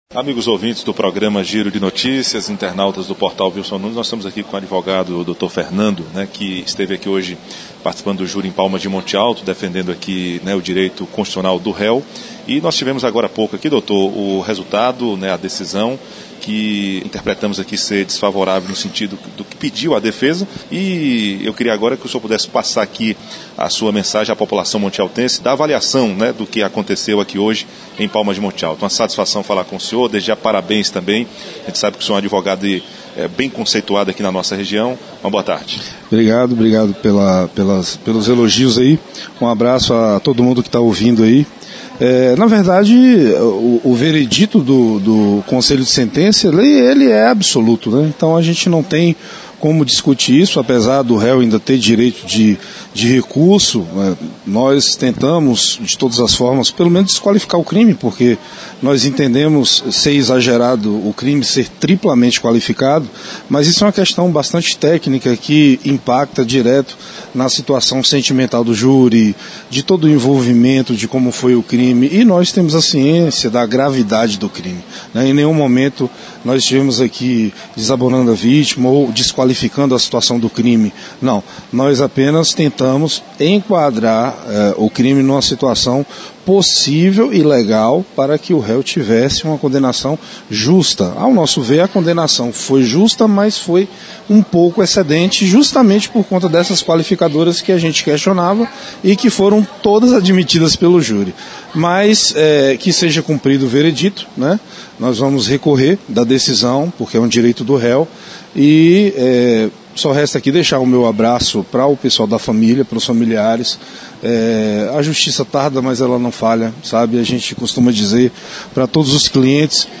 Em entrevista ao PORTAL VILSON NUNES